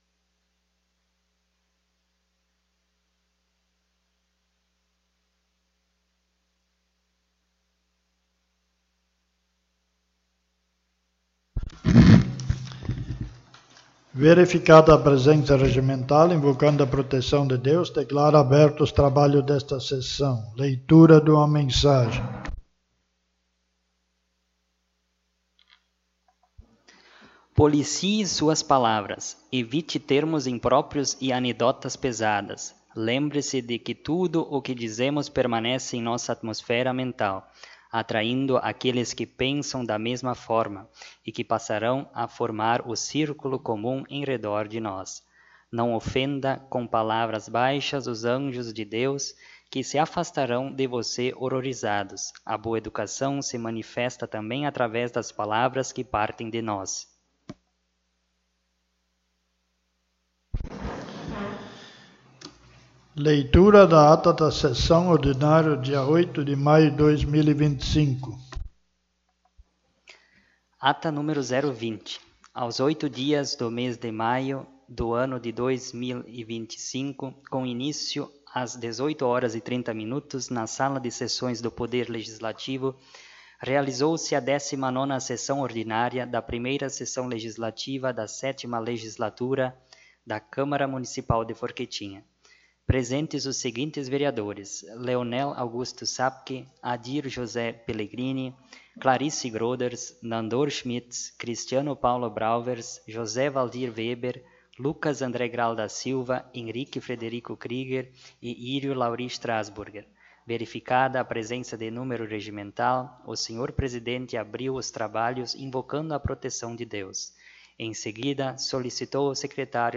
20ª Sessão Ordinária